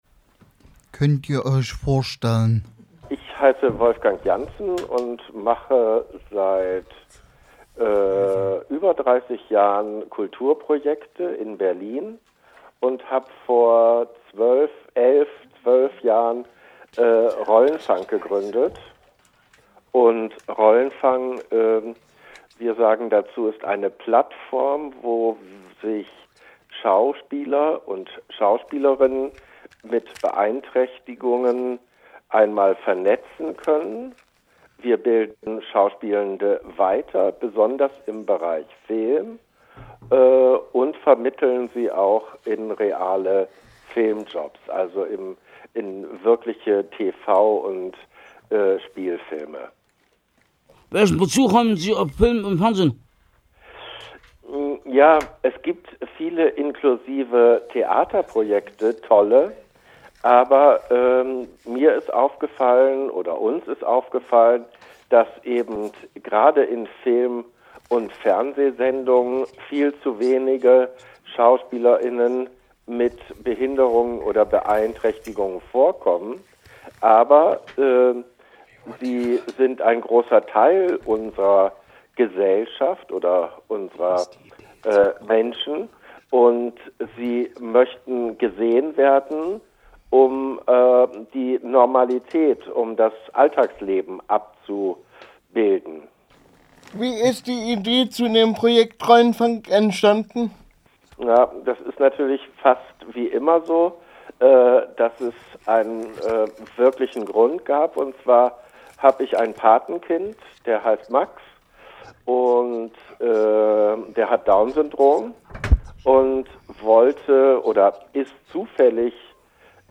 ein Telefon-Interview